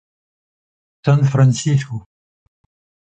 /sɑ̃ fʁɑ̃.sis.ko/